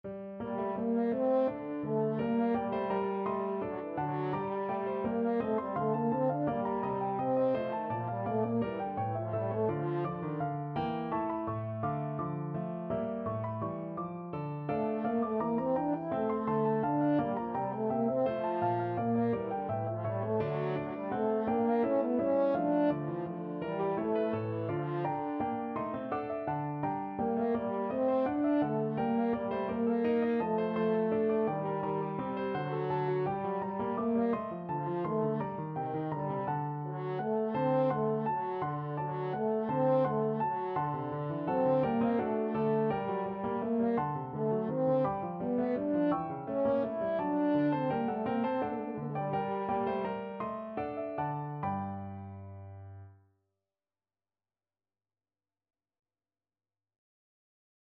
3/8 (View more 3/8 Music)
Classical (View more Classical French Horn Music)